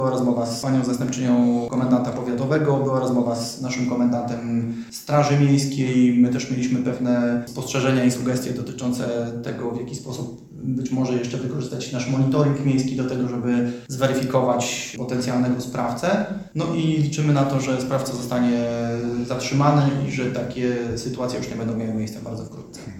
Burmistrz Krzysztof Sypień mówi, że jedyne, co obecnie może z tym fantem zrobić, to mobilizowanie mundurowych do złapania piromana.